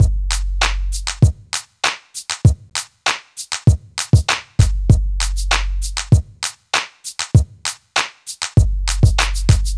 Hands Up - Beat.wav